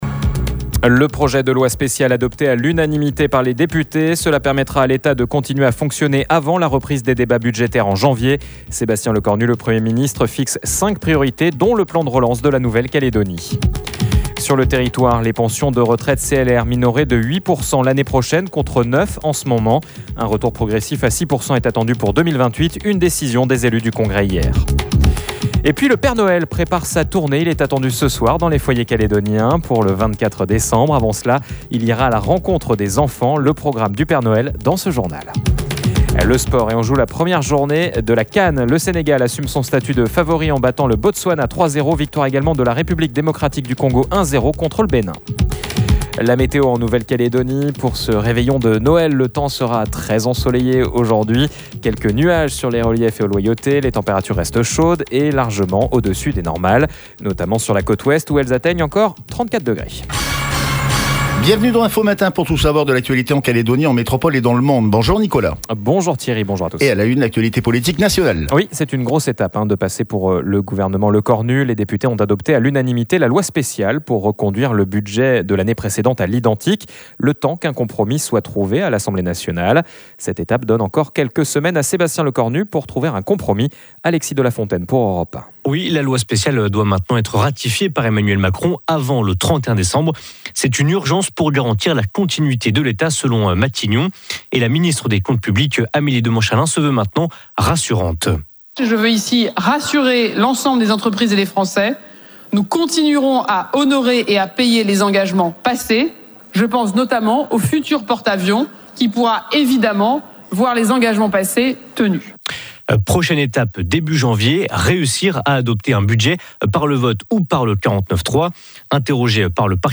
Un peu plus tôt ce matin pour nous, Sébastien Lecornu s’est exprimé depuis Matignon.